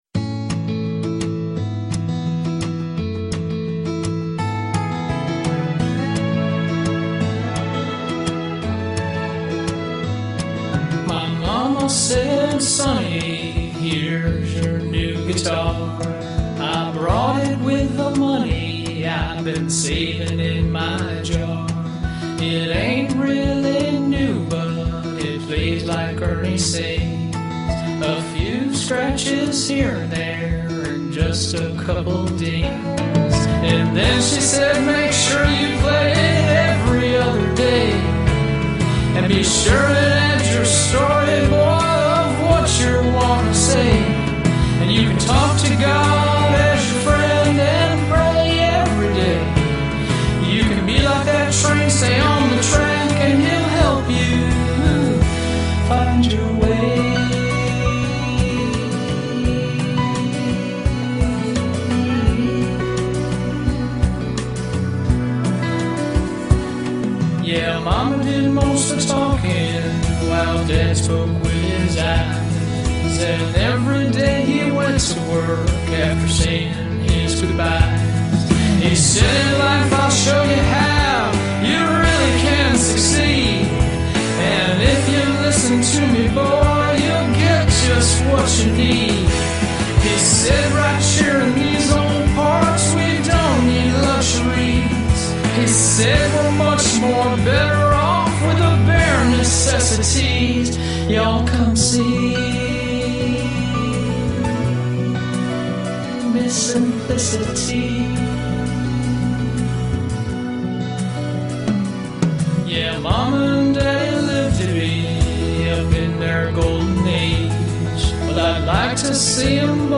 Demo Song : 3).
• 5 hours in the studio
• One vocal take (10 min.)
Lead Guitar